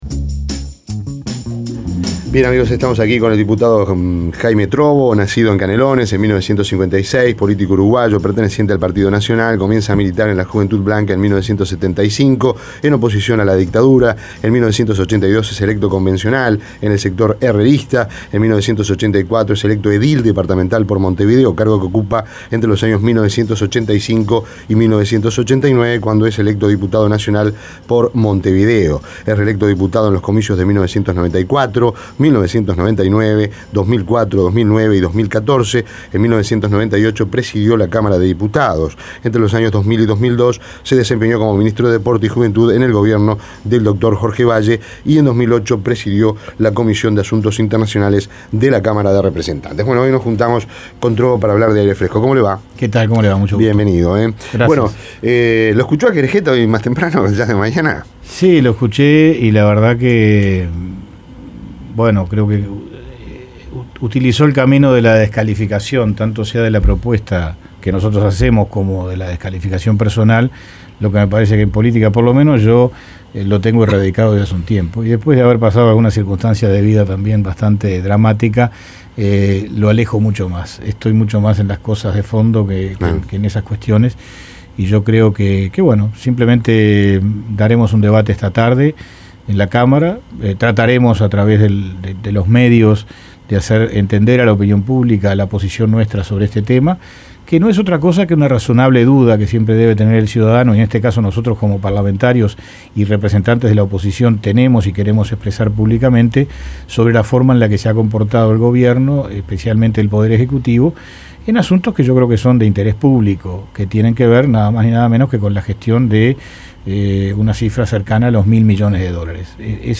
Entrevista a Trobo en Rompkbzas